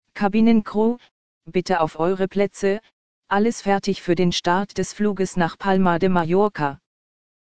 CrewSeatsTakeoff.ogg